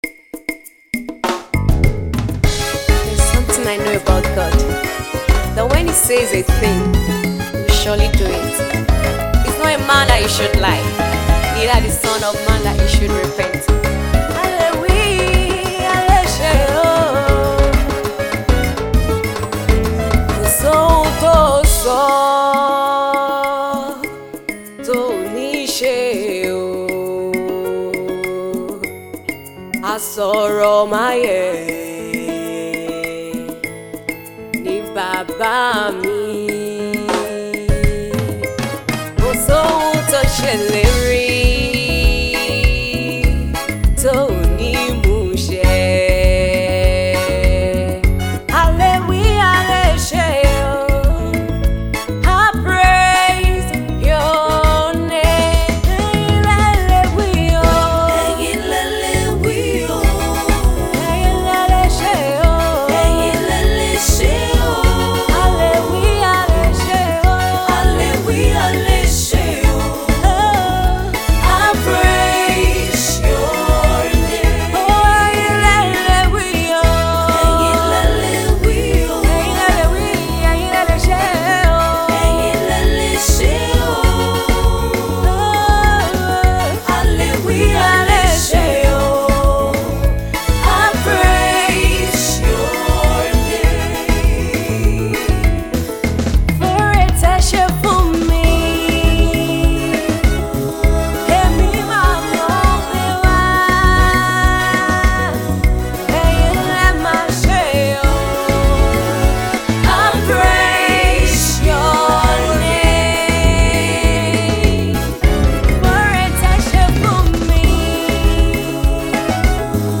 It is a song of praise and thanksgiving.